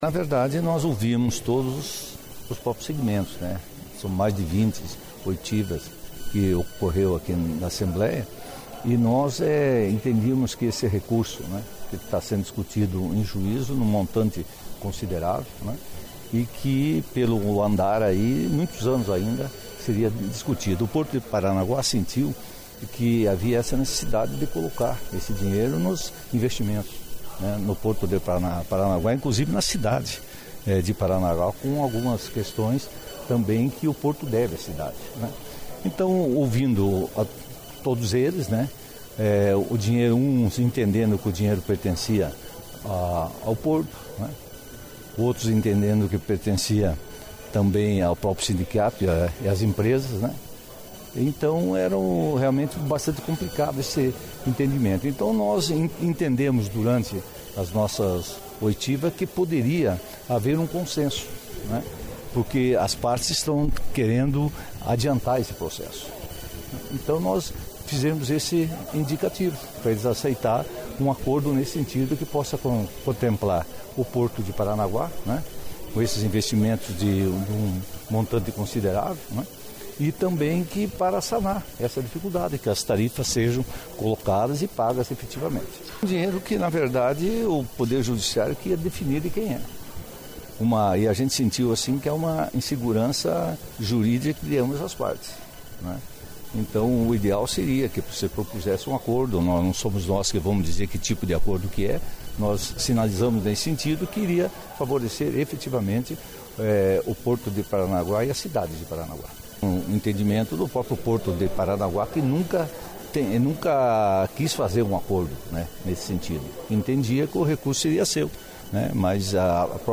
Foi o que afirmou o presidente da CPI, deputado Ademir Bier (MDB), em entrevista após a sessão plenária.